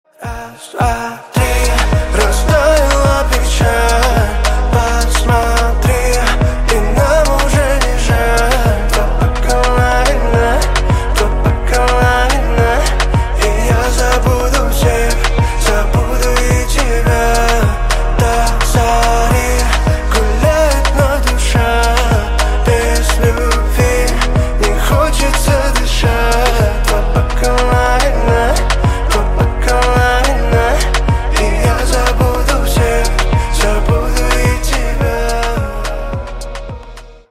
Поп Музыка
спокойные
грустные